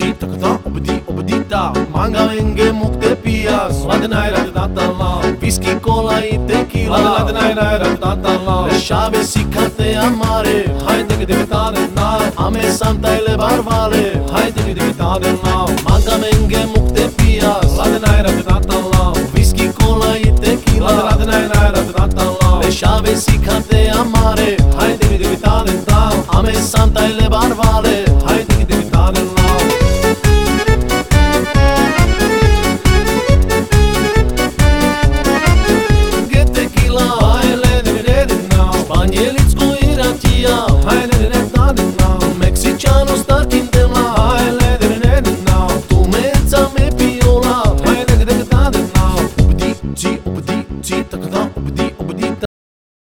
• Качество: 320, Stereo
зажигательные
веселые
цыганские